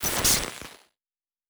pgs/Assets/Audio/Sci-Fi Sounds/Electric/Glitch 2_08.wav at master
Glitch 2_08.wav